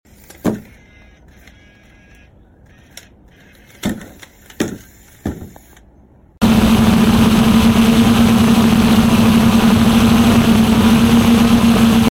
Fiber optic drone communication module,